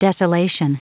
Prononciation du mot desolation en anglais (fichier audio)
Prononciation du mot : desolation